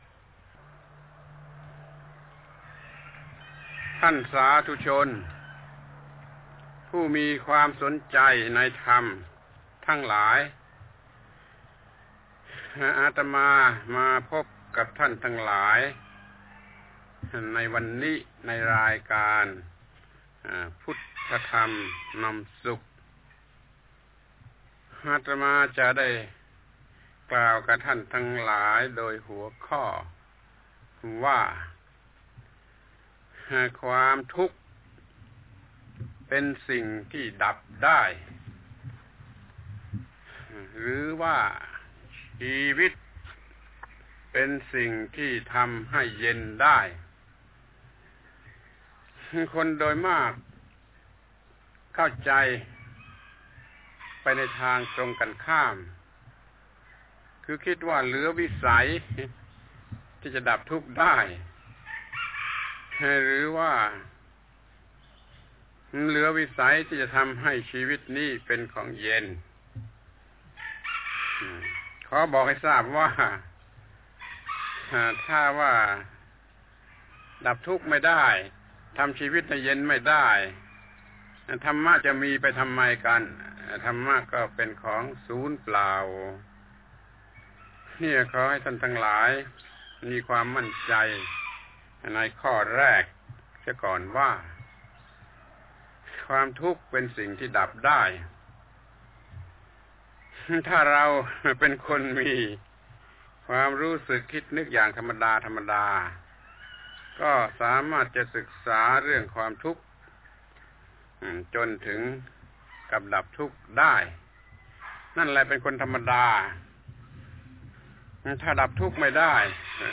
พระธรรมโกศาจารย์ (พุทธทาสภิกขุ) - ปาฐกถาธรรมทางโทรทัศน์สุราษฯและหาดใหญ่ รายการพุทธธรรมนำสุข ครั้ง ๔๘ ความทุกข์เป็นสิ่งดับได้หรือชีวิตเป็นสิ่งที่เย็นได้